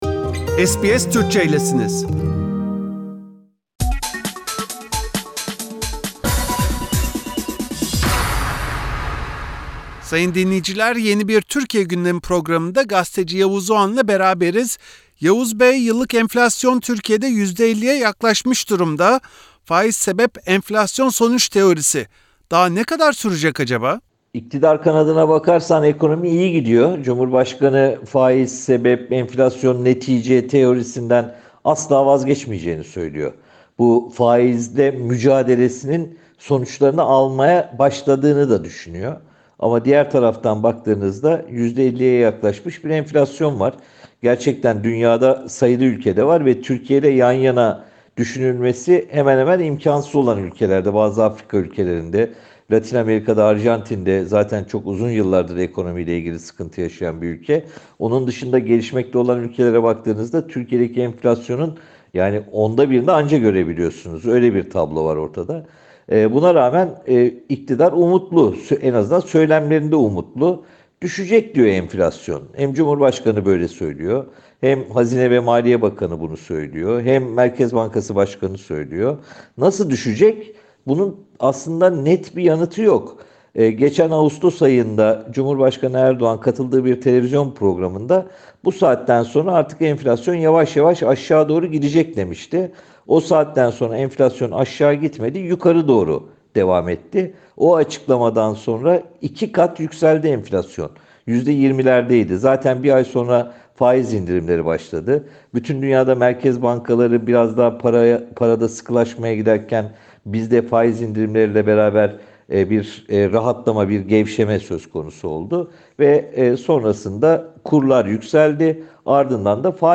Gazeteci Yavuz Oğhan Türkiye’deki siyasi, ekonomik ve diplomatik gelişmeleri SBS Türkçe için değerlendirdi.